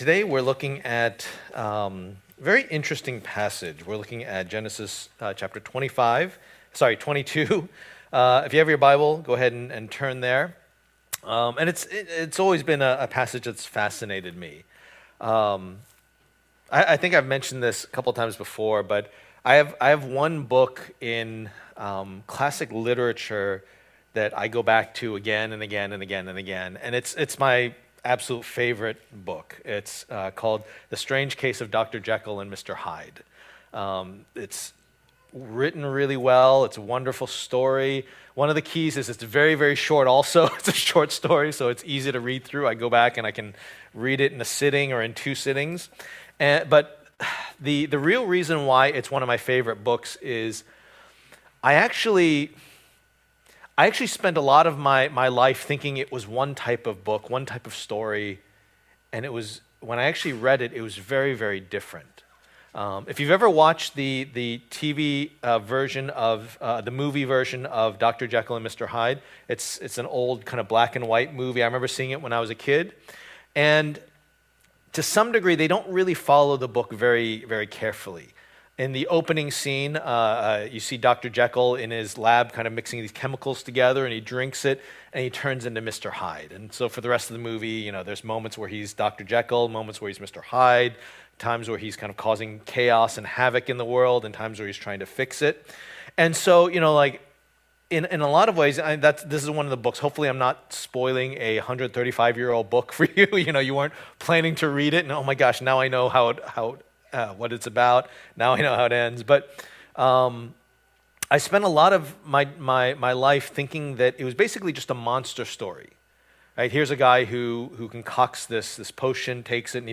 The Gospel According to Abraham Passage: Genesis 22:1-19 Service Type: Lord's Day %todo_render% « A Promise Fulfilled Finding Happiness